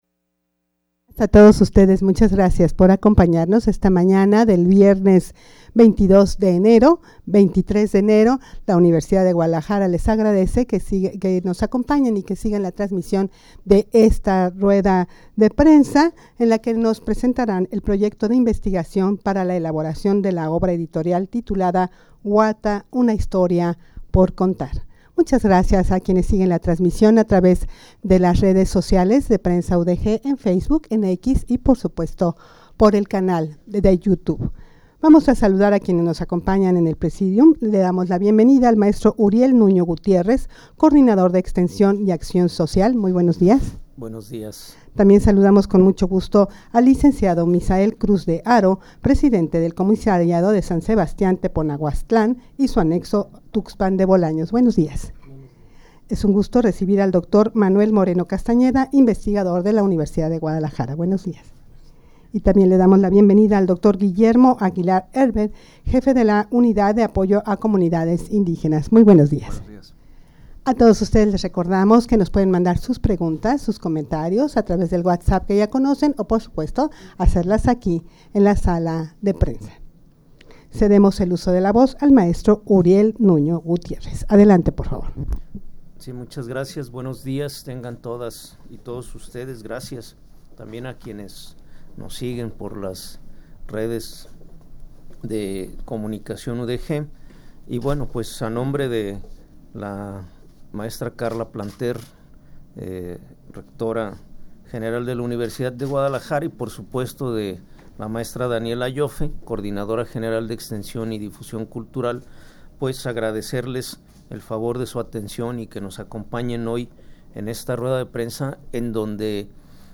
Audio de la Rueda de Prensa
rueda-de-prensa-presentacion-del-proyecto-de-investigacion-para-la-elaboracion-de-la-obra-editorial.mp3